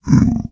Minecraft Version Minecraft Version latest Latest Release | Latest Snapshot latest / assets / minecraft / sounds / mob / zombified_piglin / zpig3.ogg Compare With Compare With Latest Release | Latest Snapshot